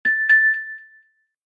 macOSsystemsounds
Keys.mp3